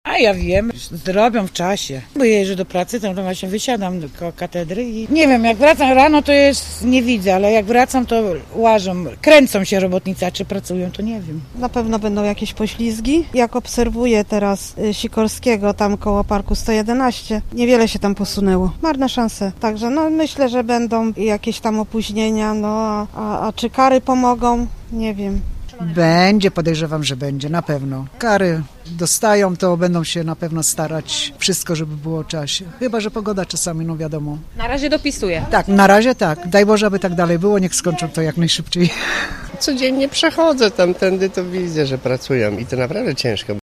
O inwestycję pytaliśmy także przechodniów.